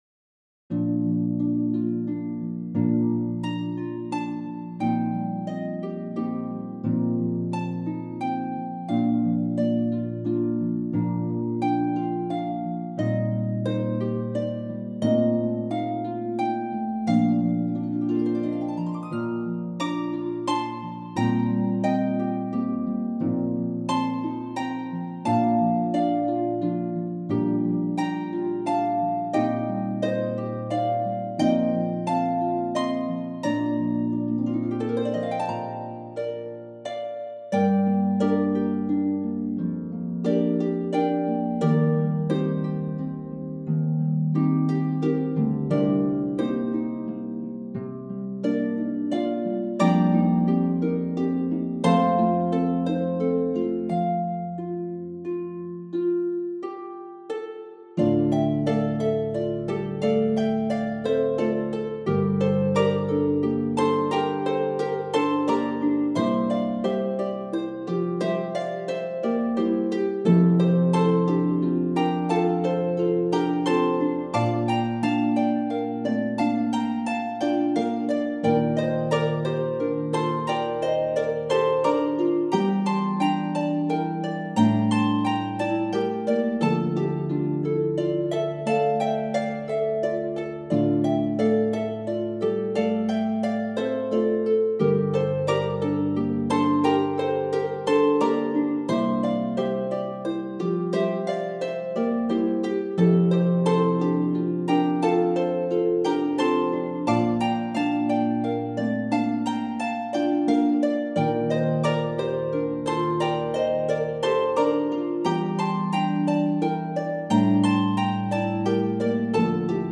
is for four or five lever or pedal harps
• Electric Harp-Bass only: $6.00